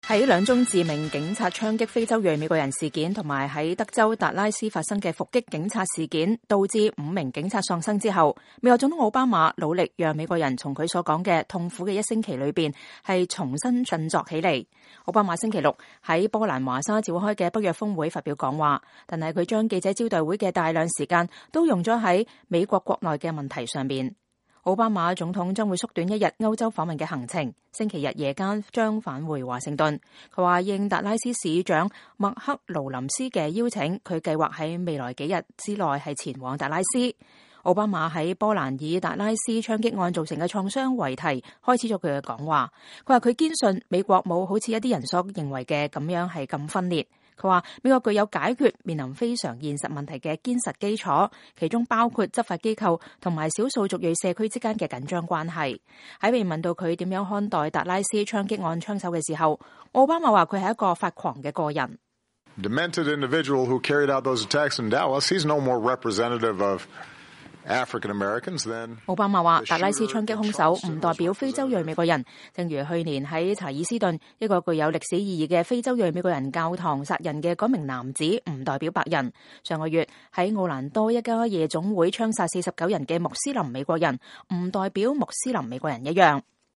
奧巴馬總統就德州達拉斯發生的伏擊警察事件在波蘭華沙發表講話。